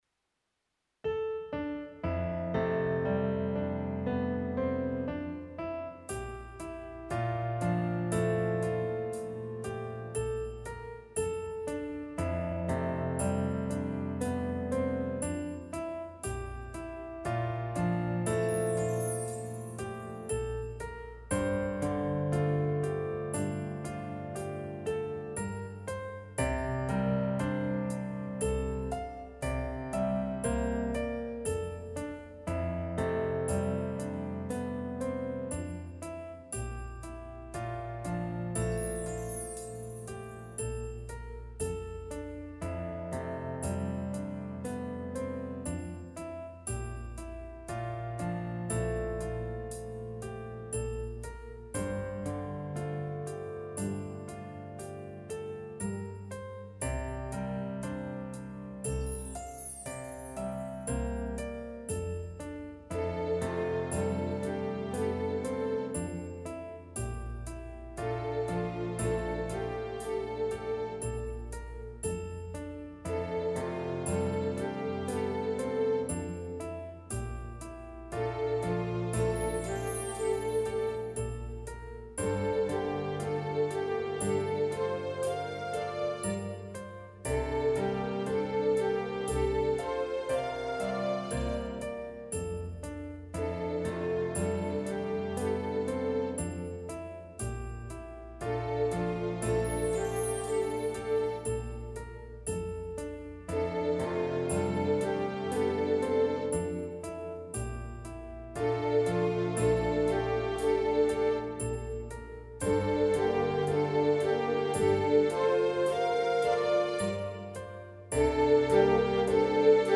DEMOS - 75% des Originaltempos
Gesamtaufnahme ohne Vokalstimmen.mp3